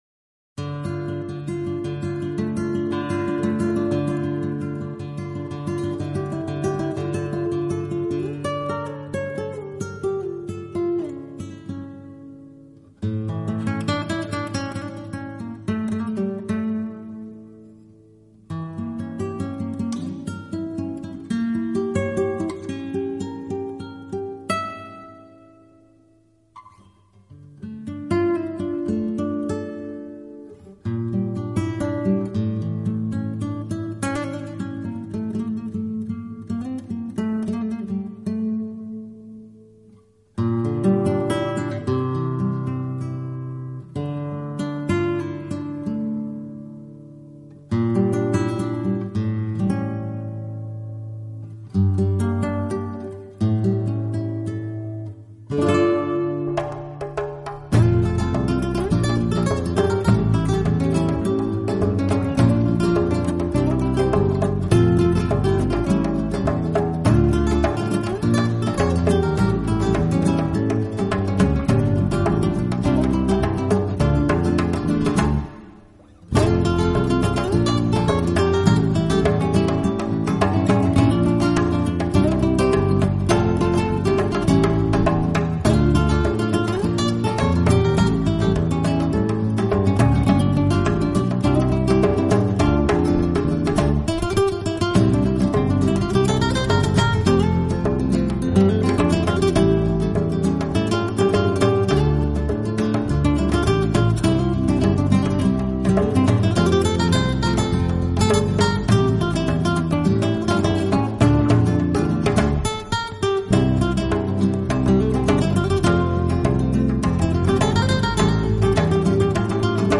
充满世纪音魂的旋律悠扬、高远，深得章法。
阵阵海风，明媚阳光的脚步，一起旋转、舞蹈……